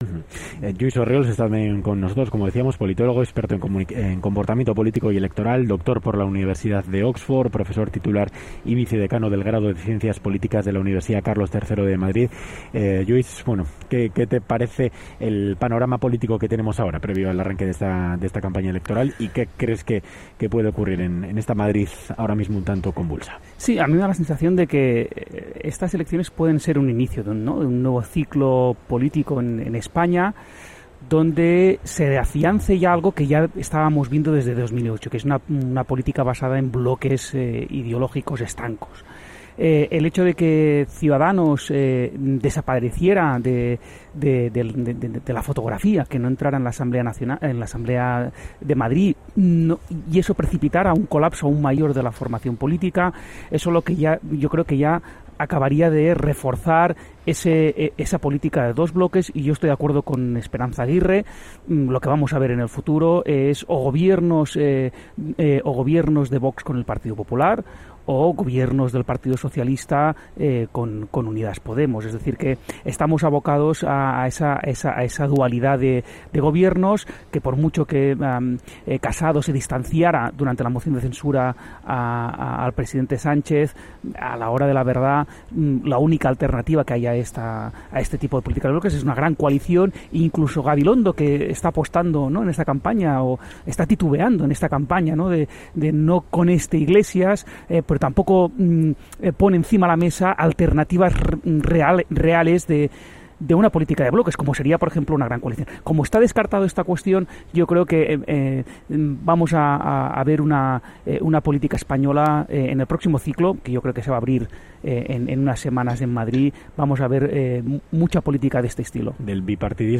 Audio: Programa especial desde Madrid.